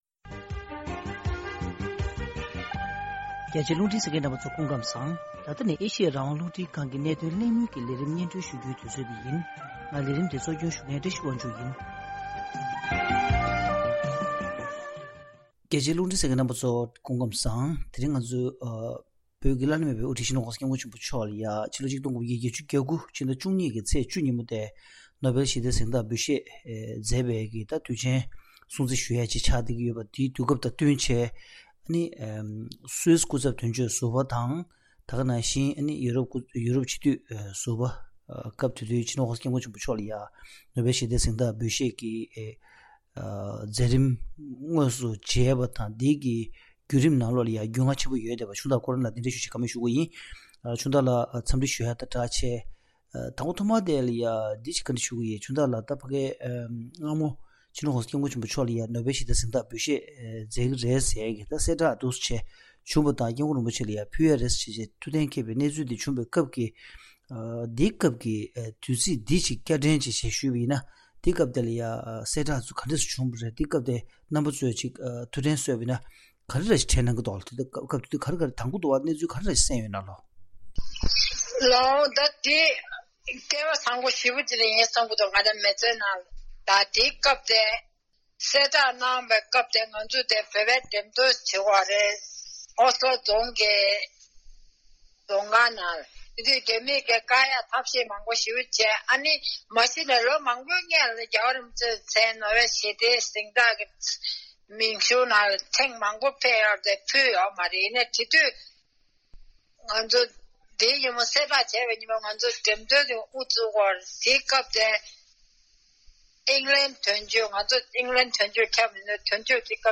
ཐེངས་འདིའི་གནད་དོན་གླེང་མོལ་གྱི་ལས་རིམ་ནང་།